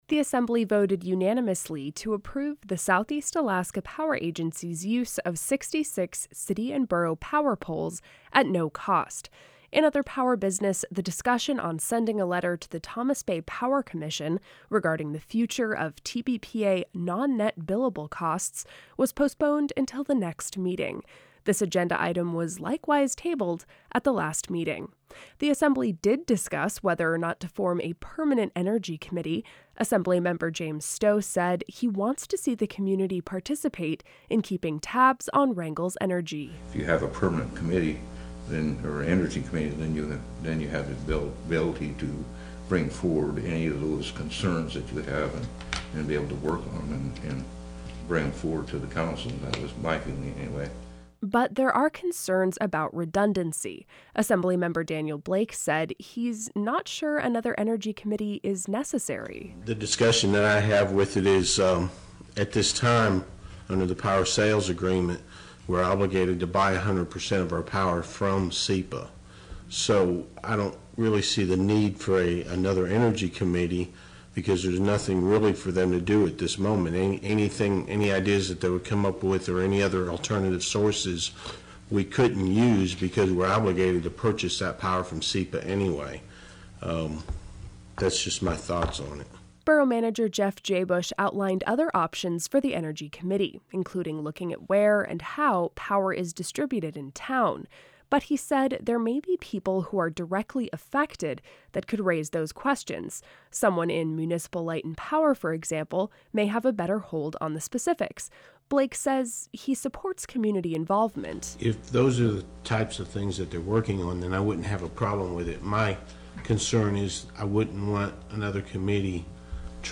Did you appreciate this report?